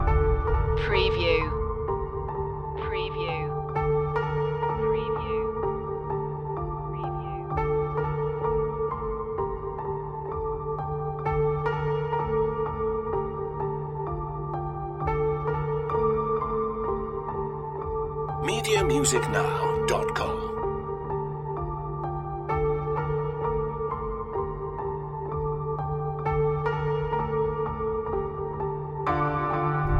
Dark reflective royalty free soundtrack music.